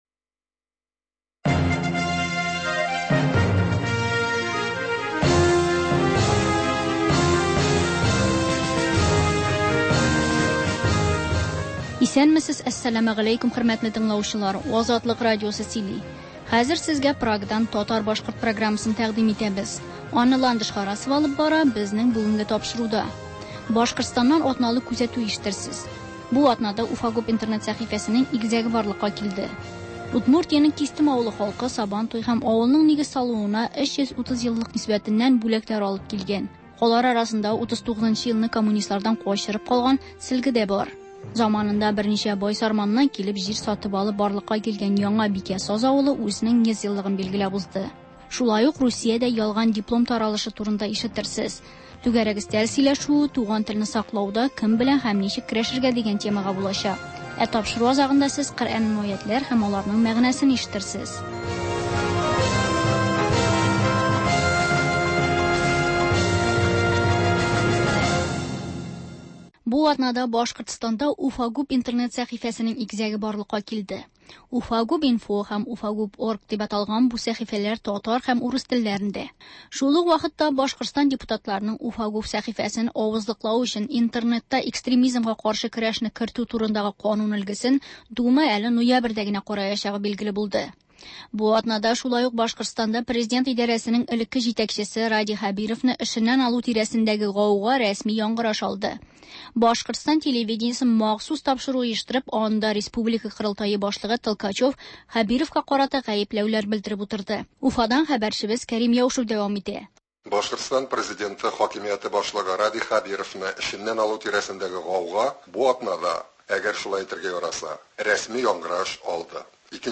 сәгать тулы хәбәр - Башкортстаннан атналык күзәтү - түгәрәк өстәл артында сөйләшү